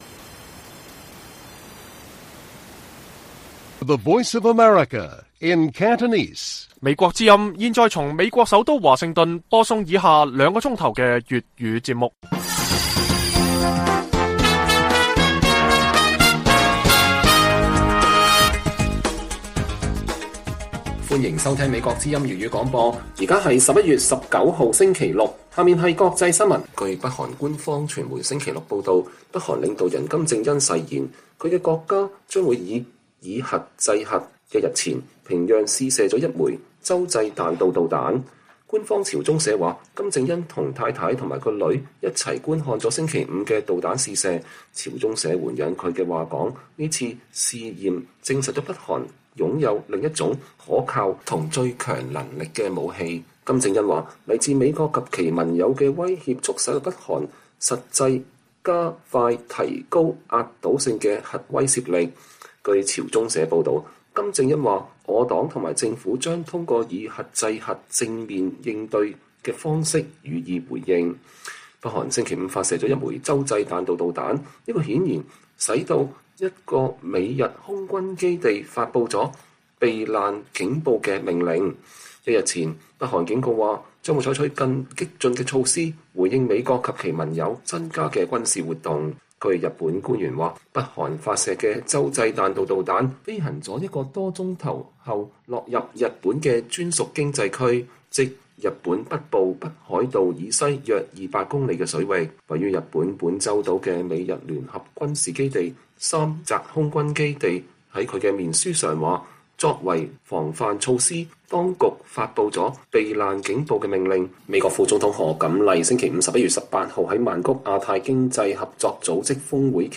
粵語新聞 晚上9-10點 ： 前英國保守黨黨魁批評英政府對中國“海外警署”行動落後